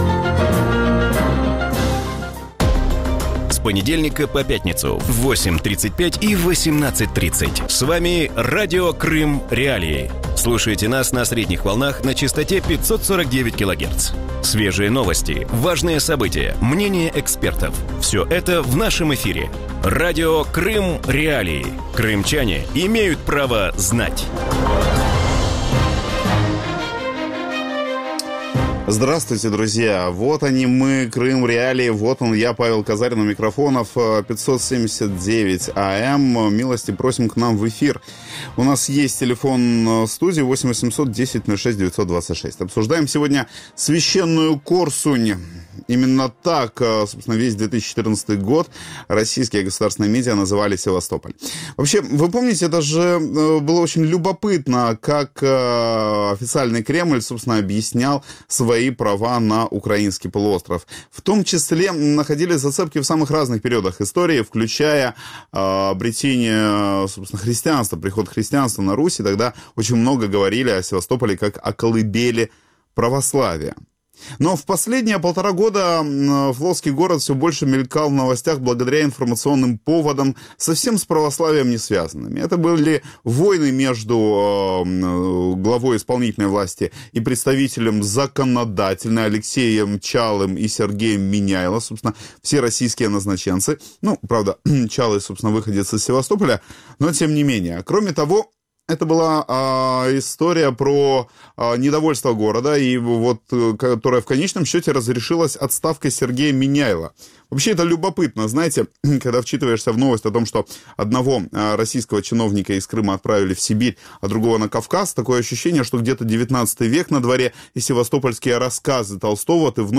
У вечірньому ефірі Радіо Крим.Реалії говорять про призначення Дмитра Овсяннікова на посаду підконтрольного Кремлю тимчасово виконуючого обов'язки губернатора Севастополя. Що означає призначення людини «без погон» у місто флоту, особливо після віце-адмірала Сергія Меняйло. Чим обернеться таке призначення для міста і севастопольців.